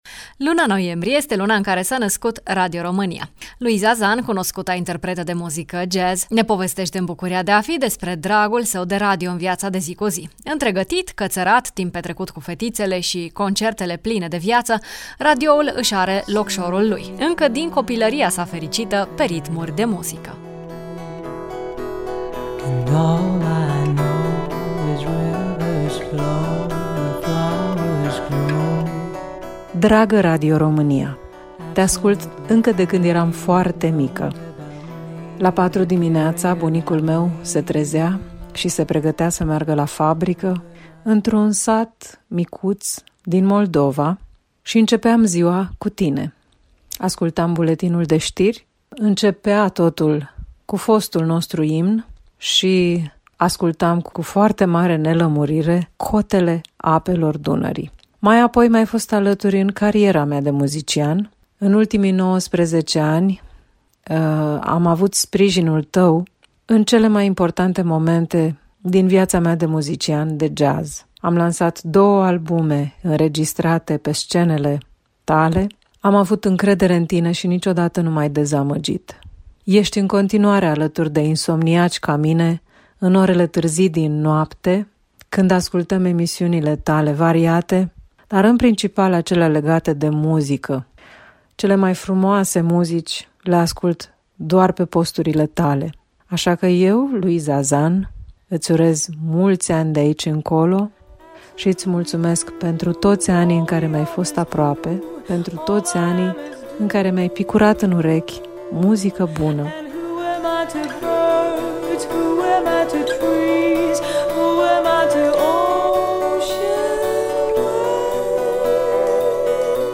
Muzicienii au o relație specială cu radioul, astăzi în Bucuria de a fi, Luiza Zan ne povestește cum se împletesc jazz-ul cu Radio România, într-o urare de ziua noastră: